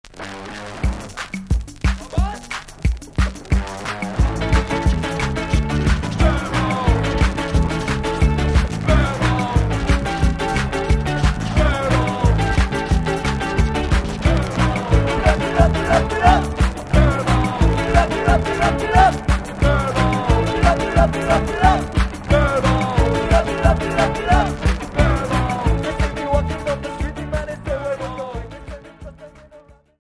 Punk Alternatif